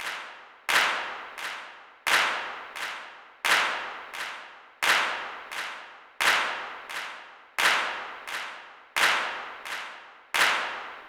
Clap.wav